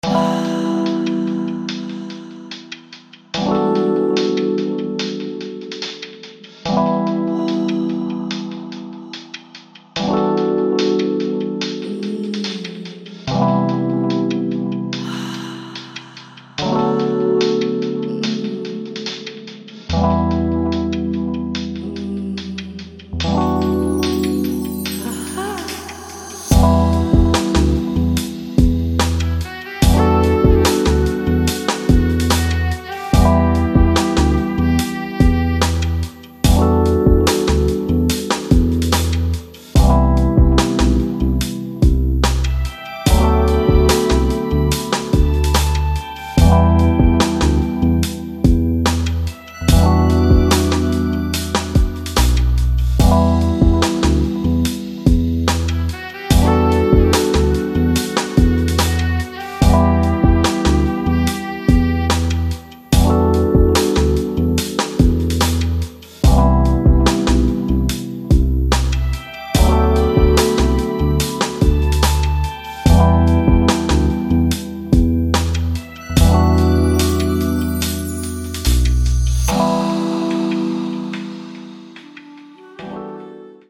De vocals zijn van mij persoonlijk.